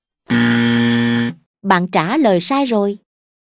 Tiếng chuông Bạn Trả Lời Sai Rồi (Nữ, Miền Nam)
Thể loại: Hiệu ứng âm thanh
Description: Tiếng chuông "Bạn Trả Lời Sai Rồi" với giọng nữ miền Nam dễ thương, nhẹ nhàng là hiệu ứng âm thanh phổ biến dành cho giáo viên mầm non, mẫu giáo.
Âm thanh sai, nhắc nhở nhẹ nhàng, không gây áp lực.
tieng-chuong-ban-tra-loi-sai-roi-nu-mien-nam-www_tiengdong_com.mp3